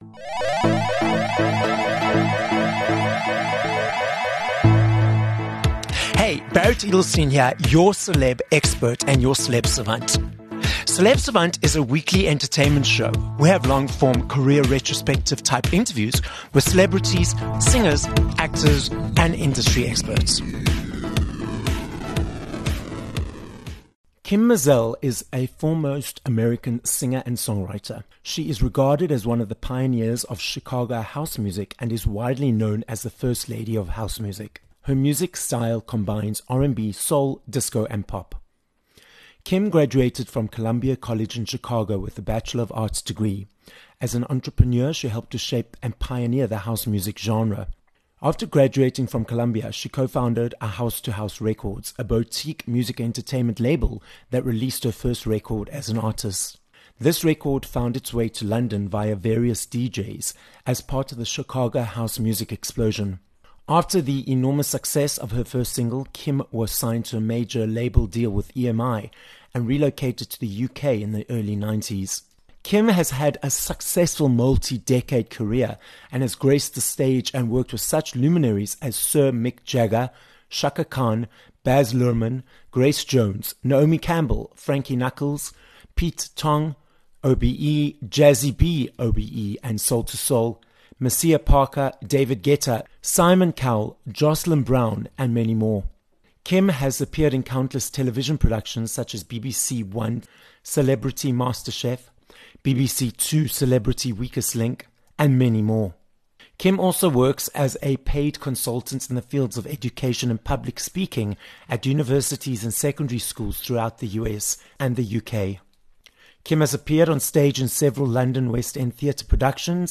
31 Dec Interview with Kym Mazelle
Having become WhatsApp friends after collaborating on an interview for another podcast, I decided to invite American singer and songwriter Kym Mazelle as a guest on this episode of Celeb Savant. We hear about Kym’s successful double Grammy-nominated, multi-decade in the music business, and how as a pioneer in the genre she is known as ‘The First Lady of House Music’.